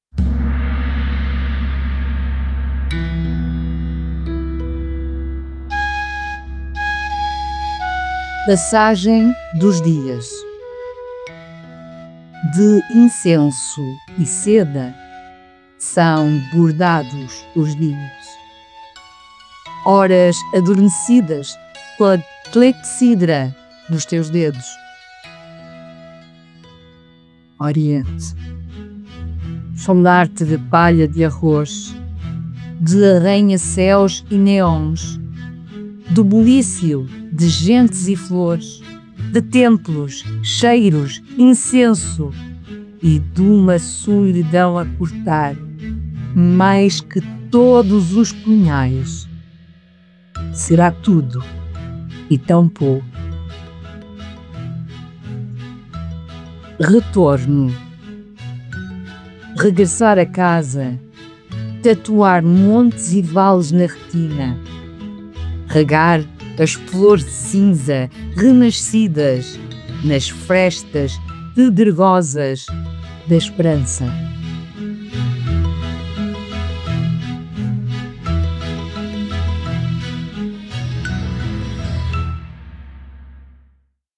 Música: Bastiana, tradicional macaense, licença Cantar Mais.